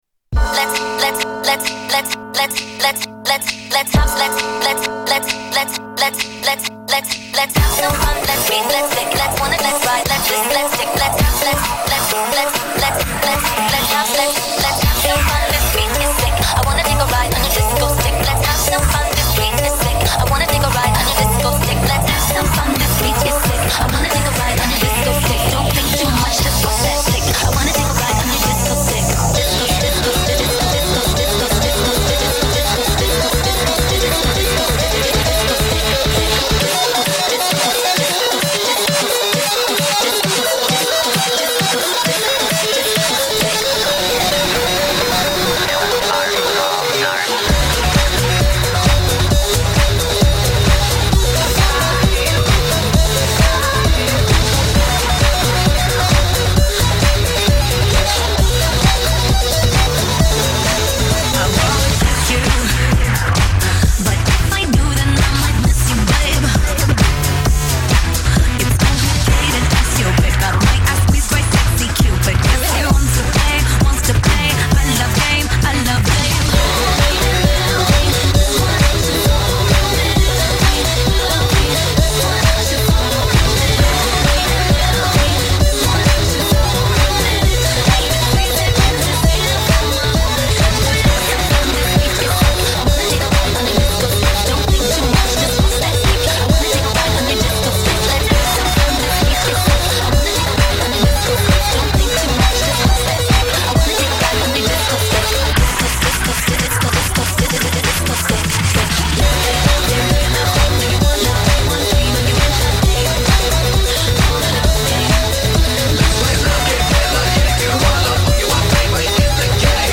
Mash Up music